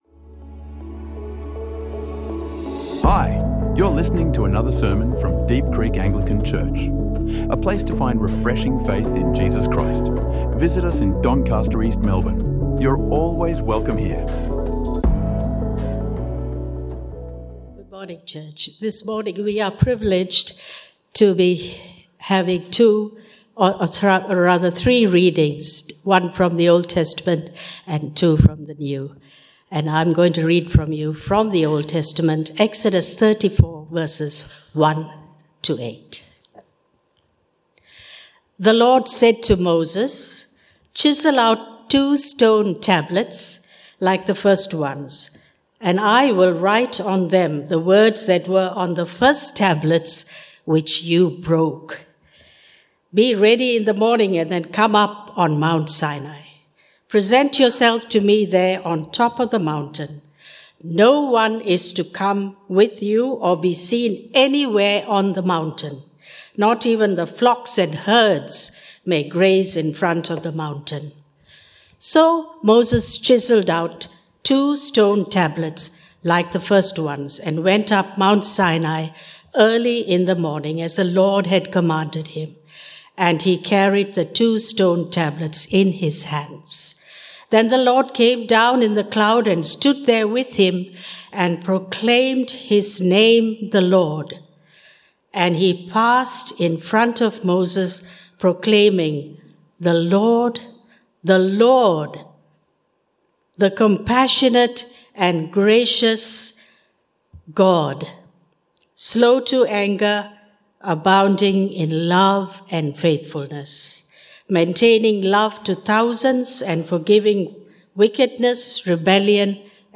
Still Saying Ancient Creeds and Prayers | Sermons | Deep Creek Anglican Church